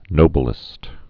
(nō-bĕlĭst)